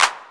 Cardiak Clap.wav